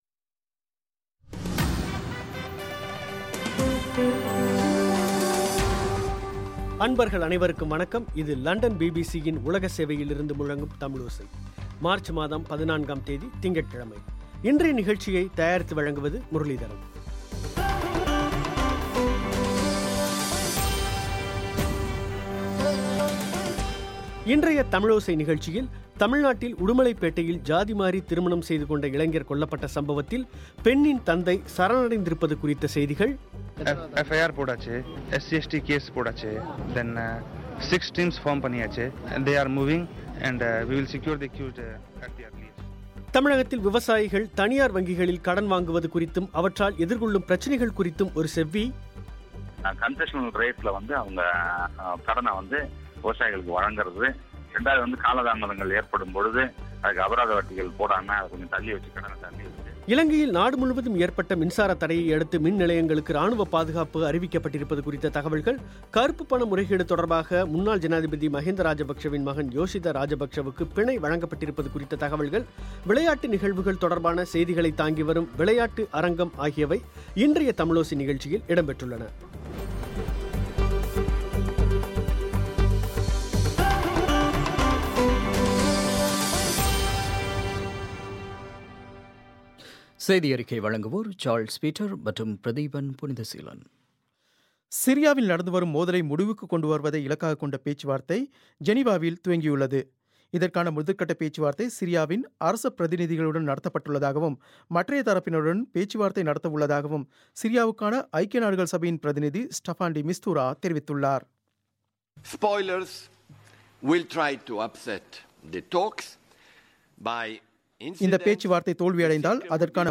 தமிழ்நாட்டில் விவசாயிகள் தனியார் வங்கிகளில் கடன்வாங்குவது குறித்தும் அவற்றால் எதிர்கொள்ளும் பிரச்சனைகள் குறித்தும் ஒரு செவ்வி;